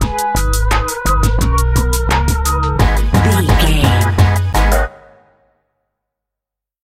Aeolian/Minor
G#
drum machine
synthesiser
funky